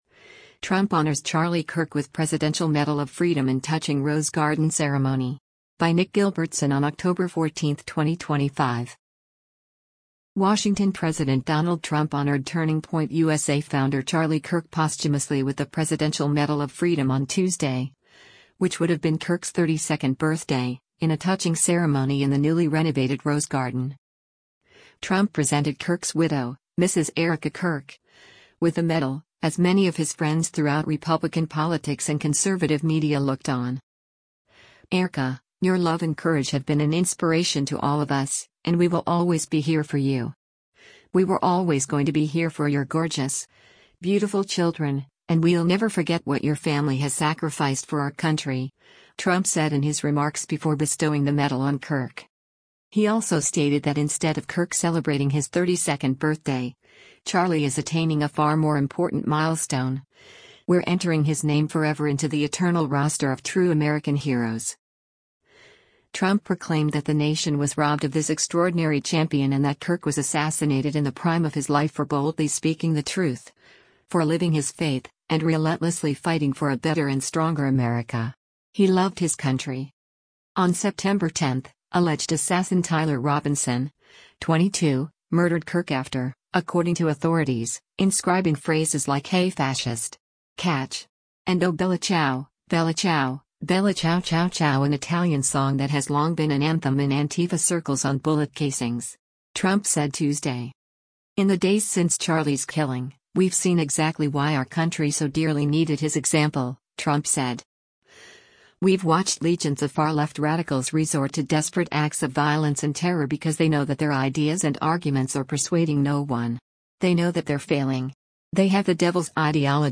Trump Honors Charlie Kirk with Presidential Medal of Freedom in Touching Rose Garden Ceremony
WASHINGTON–President Donald Trump honored Turning Point USA Founder Charlie Kirk posthumously with the Presidential Medal of Freedom on Tuesday, which would have been Kirk’s 32nd birthday, in a touching ceremony in the newly renovated Rose Garden.